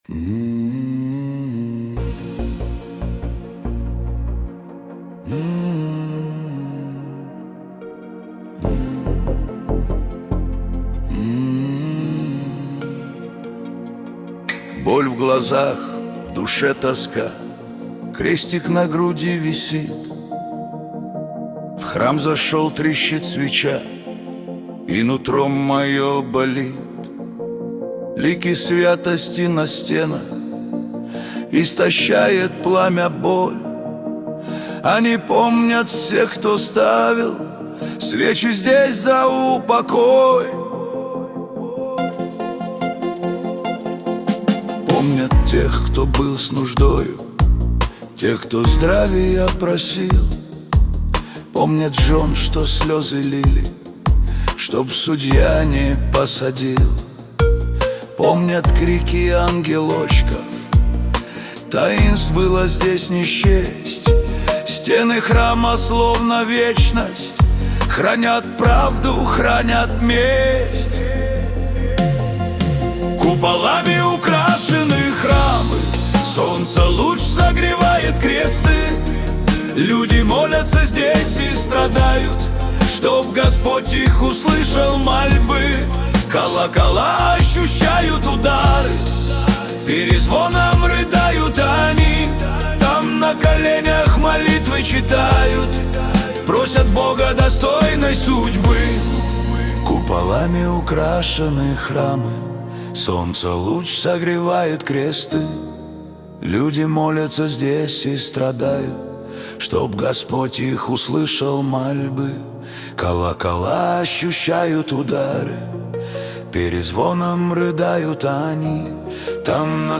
Качество: 320 kbps, stereo